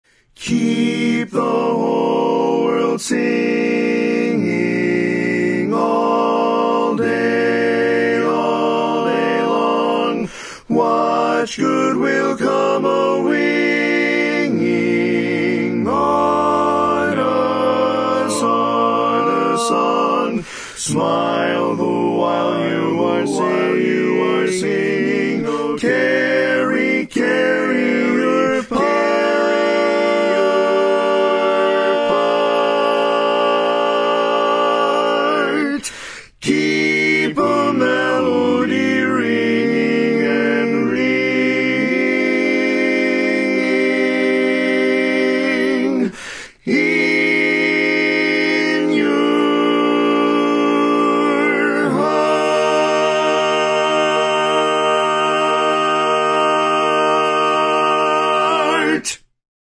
Lead Part
Keep the Whole World Singing - Lead.mp3